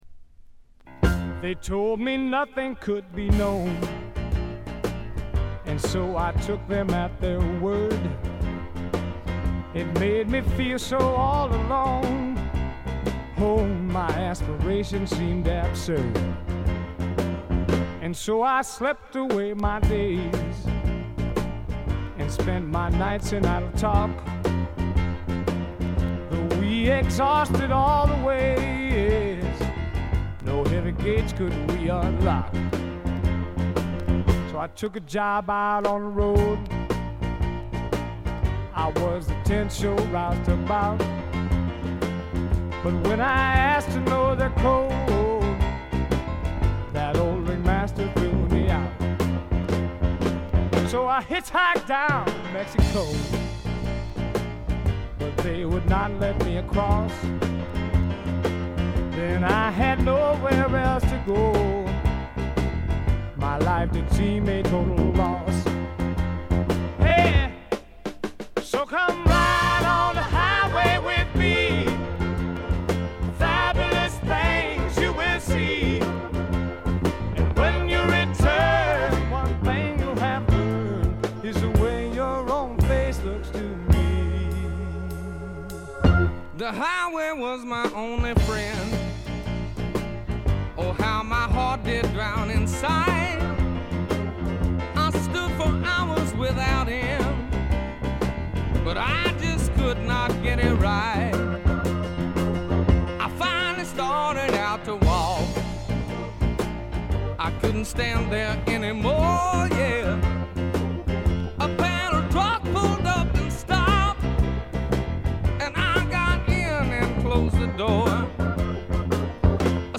ほとんどノイズ感無し。
ブルース･ロックとスワンプ・ロックを混ぜ合わせて固く絞ったような最高にグルーヴィでヒップなアルバムです。
試聴曲は現品からの取り込み音源です。